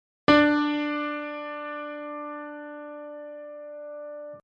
Alla näet ja kuulet duuriasteikkoiset intervallit D-duurissa.
>oktaavi
audio08oktaavi.mp3